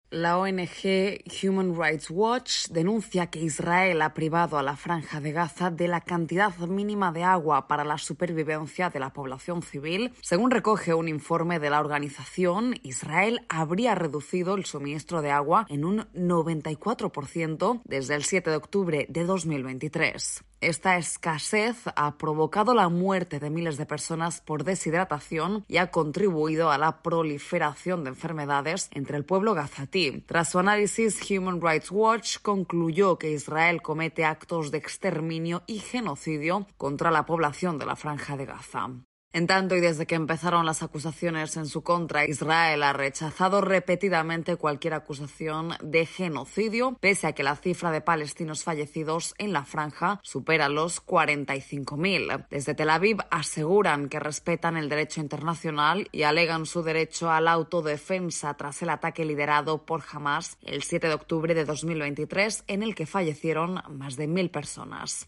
AudioNoticias
desde la Voz de América en Washington, DC.